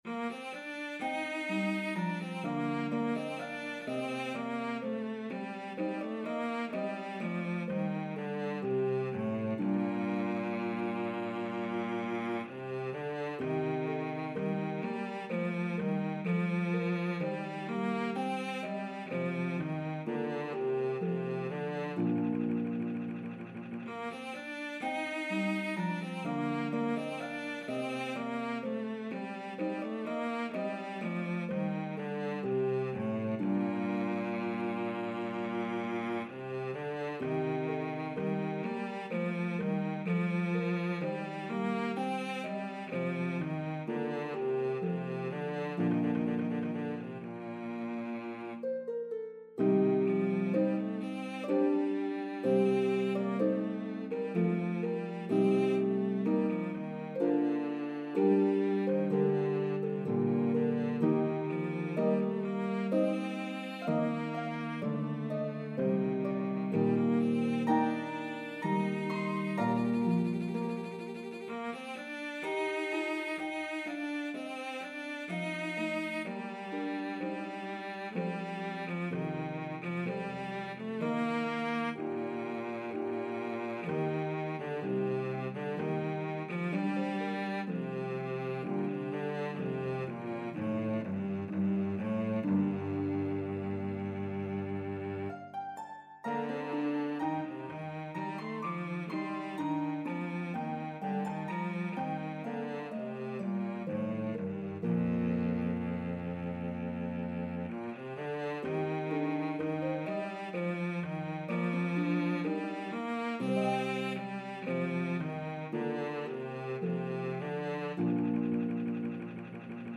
Italian Baroque style pieces
lovely slow air